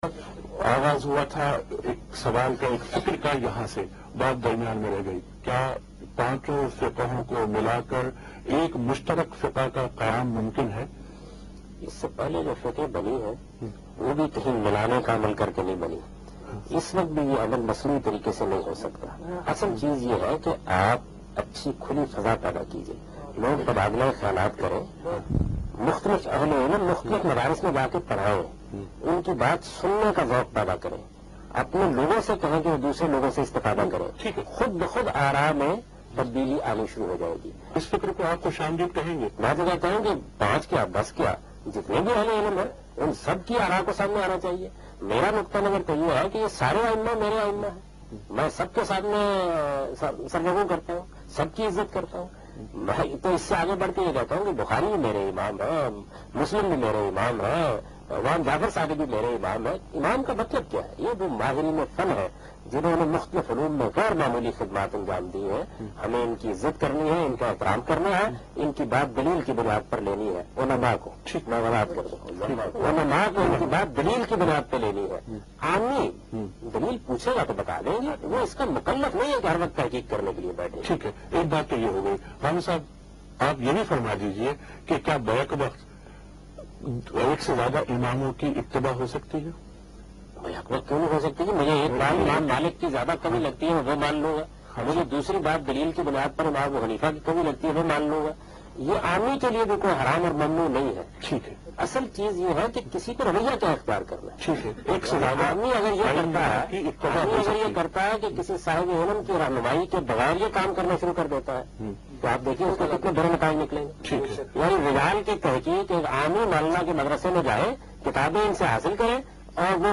TV Programs